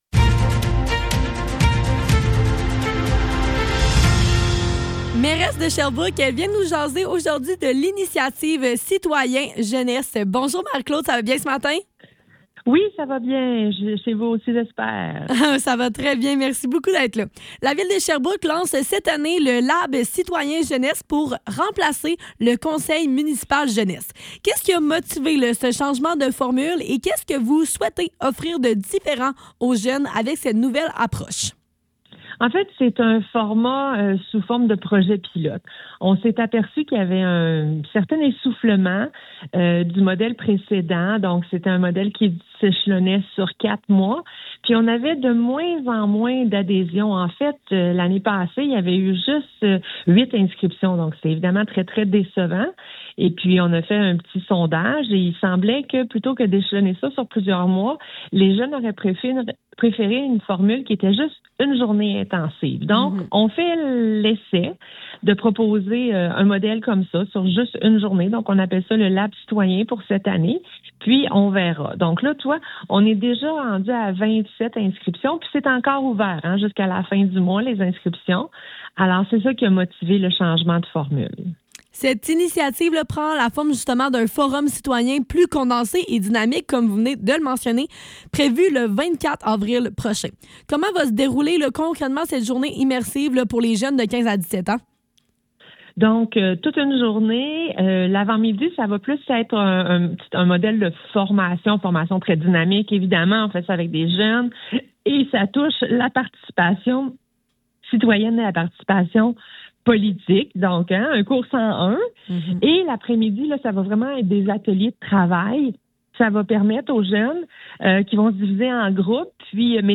Le Neuf - Entrevue avec la mairesse de Sherbrooke Marie-Claude Bibeau - 19 mars 2026